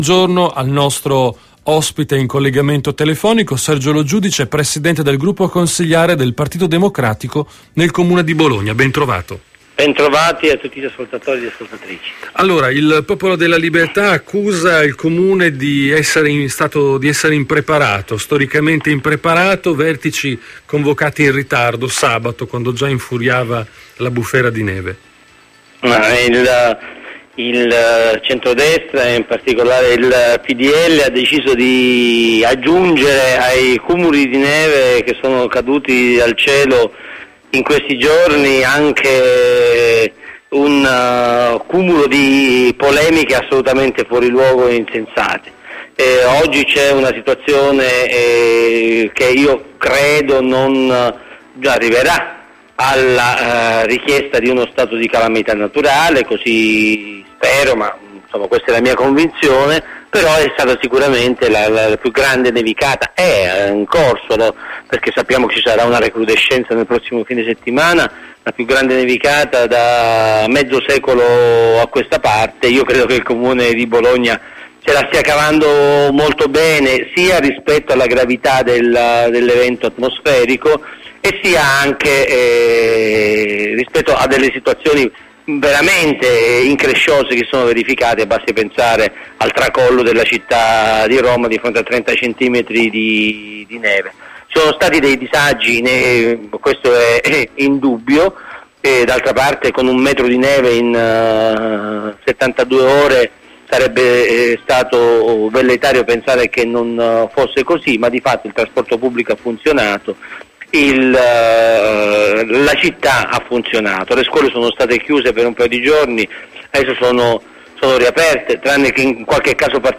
Intervista a Radio Tau del capogruppo PD Sergio Lo Giudice 8 febbraio 2012